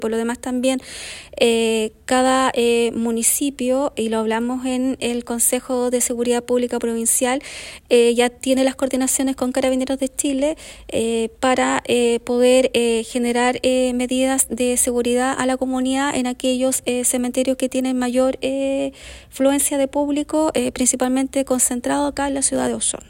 La Delegada Pailalef enfatizó que la implementación de este plan busca reforzar la seguridad y el orden público en la Provincia de Osorno, en colaboración con Carabineros y otros servicios de seguridad.